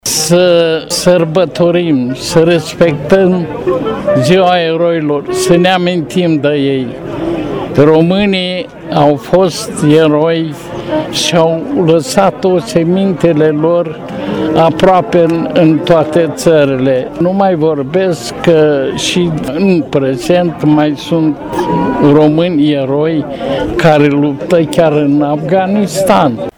La manifestarea de la Cimitirul Eroilor au fost prezenți și veterani de război, unul dintre ei fiind decorat pentru faptele de vitejie de Regele Carol I:
veteran-razboi-ziua-eroilor-brasov-2018.mp3